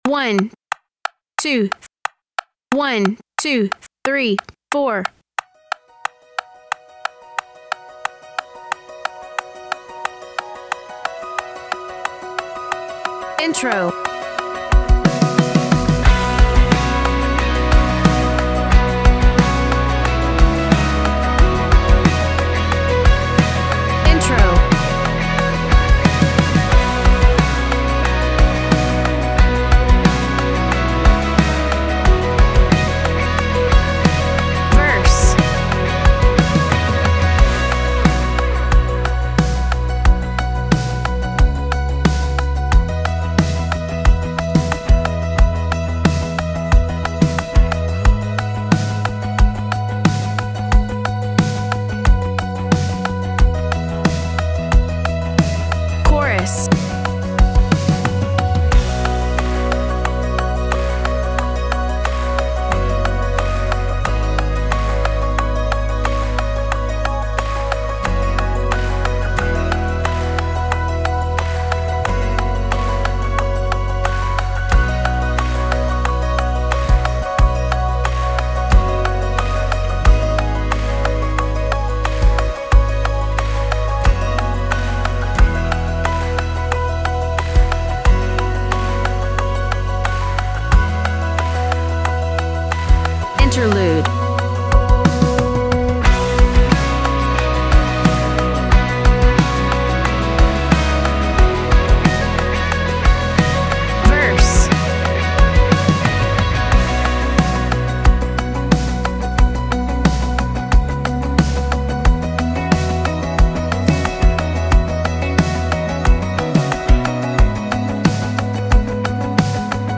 De Leeuw en het Lam Db - tracks and guide and click.mp3